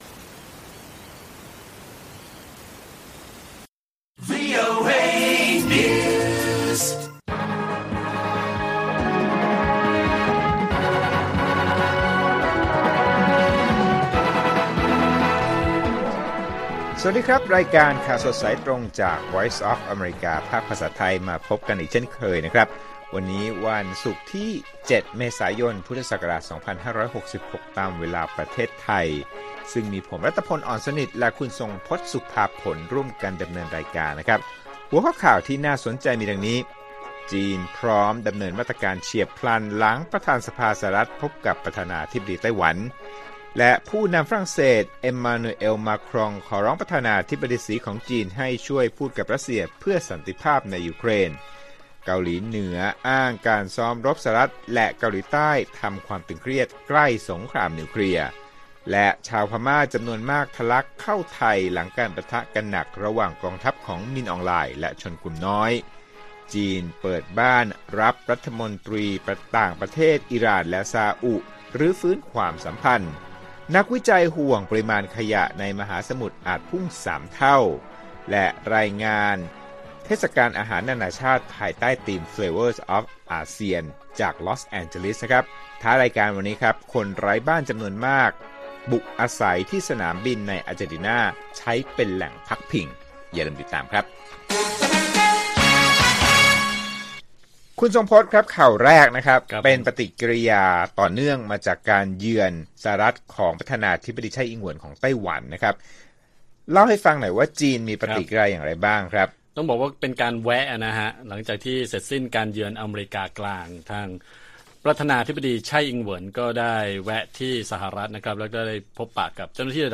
ข่าวสดสายตรงจากวีโอเอไทย ศุกร์ ที่ 7 เม.ย. 66